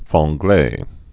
(fräɴ-glā)